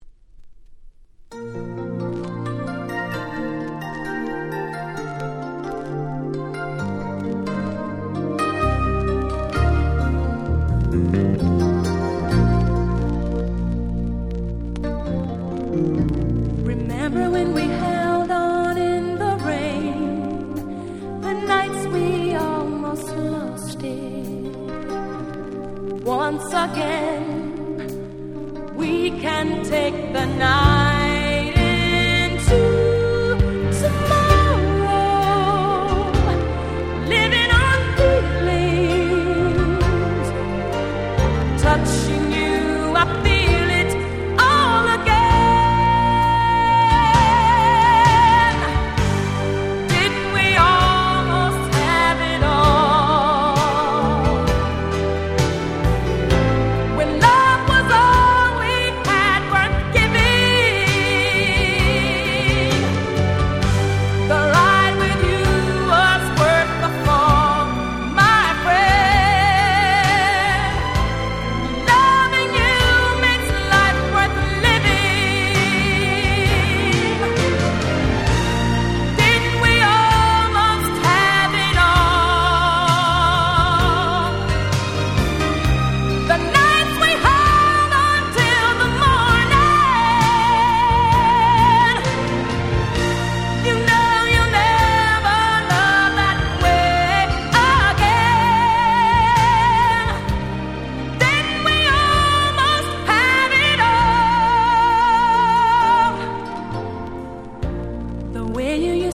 87' Super Hit R&B / Slow Jam !!